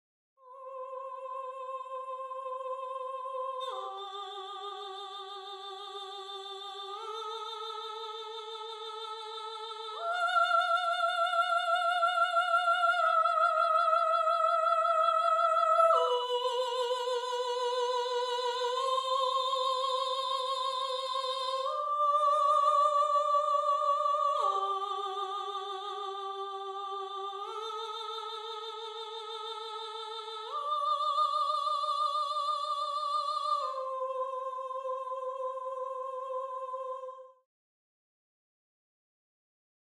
ALTO
TENOR
BASS
1. SOPRANO (Soprano/Soprano)